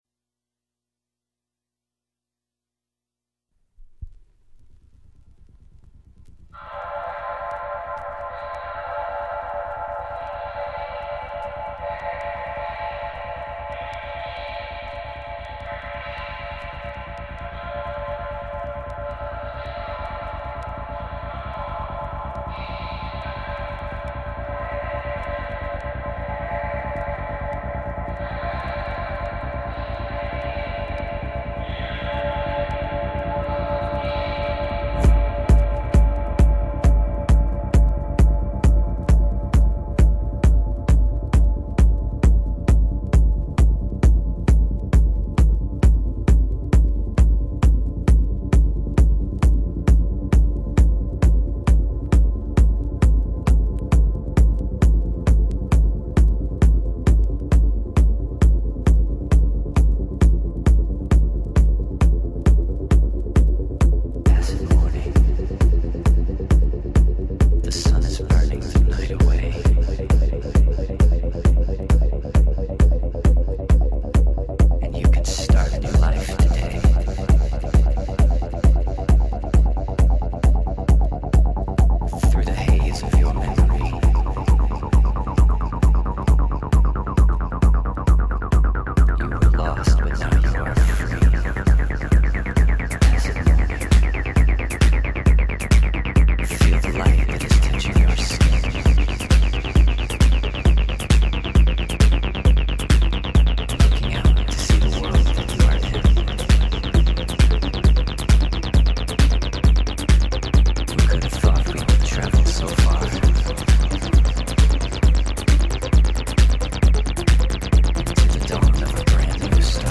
pumpin´Mix